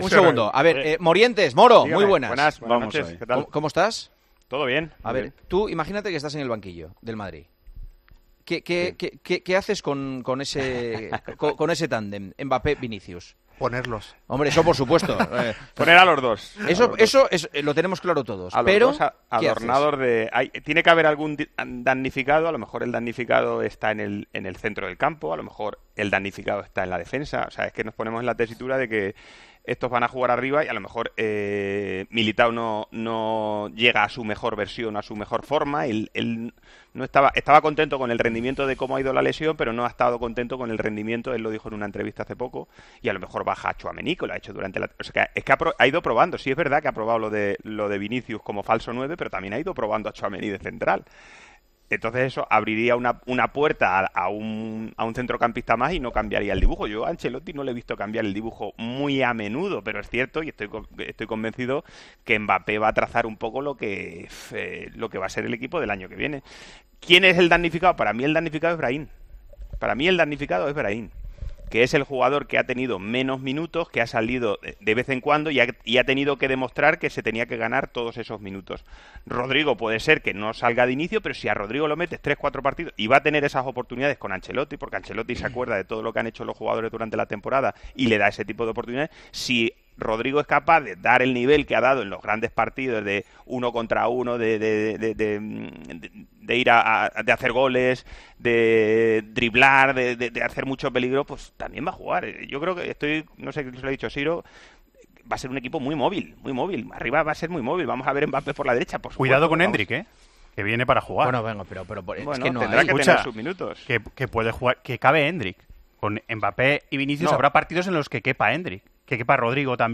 Durante El Partidazo, Juanma Castaño y los tertulianos del programa han analizado la situación de la plantilla del Real Madrid y cómo se podrán dividir los minutos en un equipo que cuenta con un gran número de jugadores en la parcela ofensiva y que tendrá que hacer una selección sobre los integrantes que formarán parte del equipo la temporada que viene.